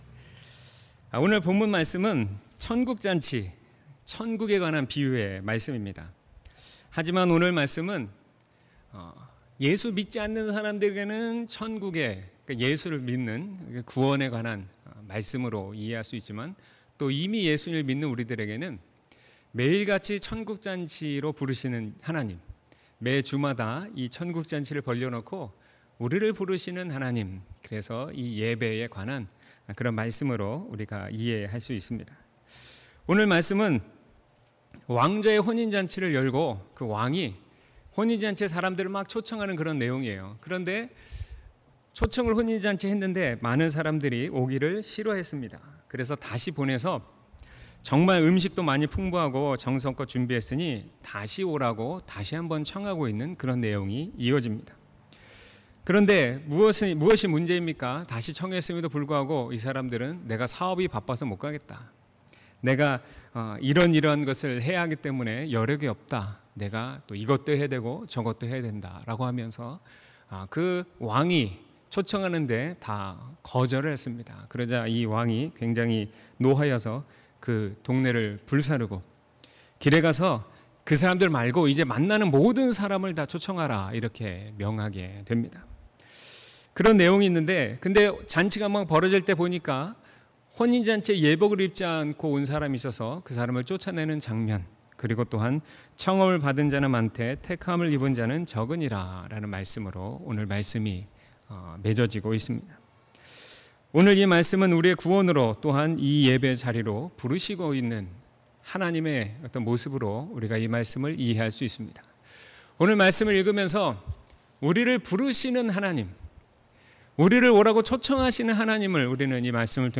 2019년 7월 14일 주일 설교/ 우리를 부르시는 하나님/ 마22:1-14